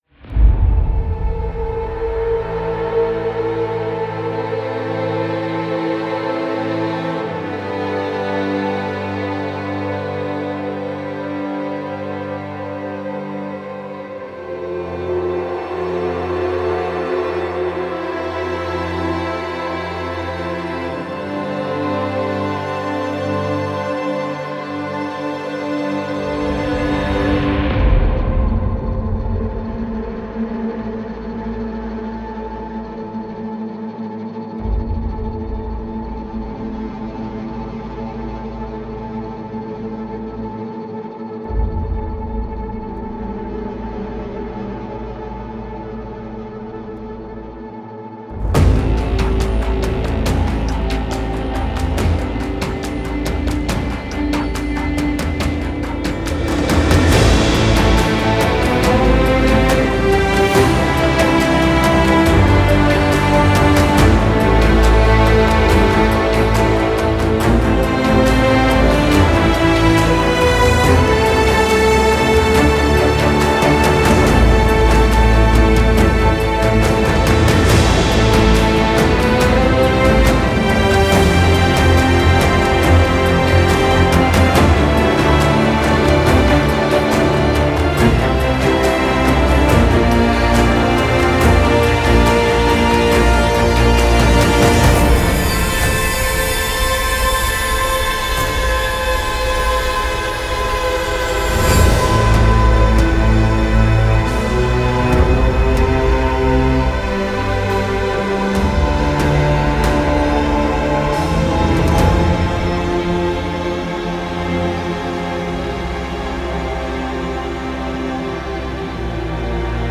موسیقی بازی
موسیقی بیکلام
موسیقی حماسی